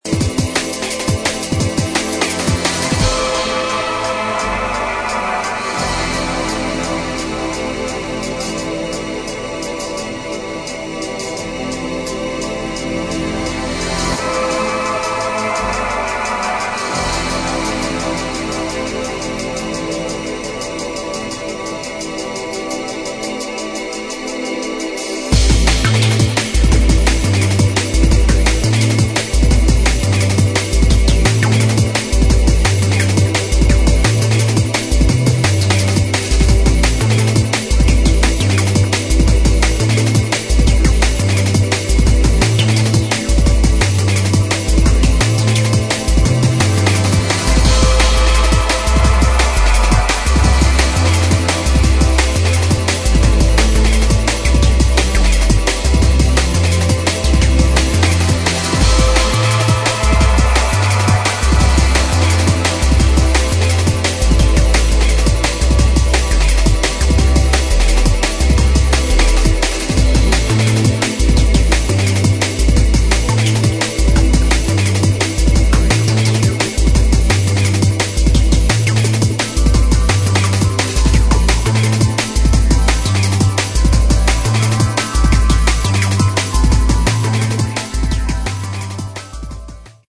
[ DRUM'N'BASS / JUNGLE ]